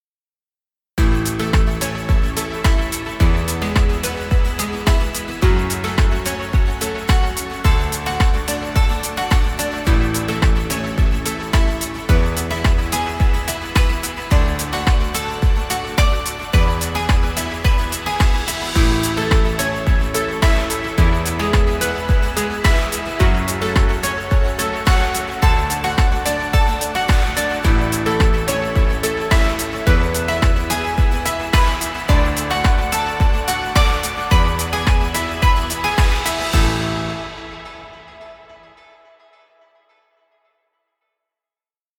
Happy positive music..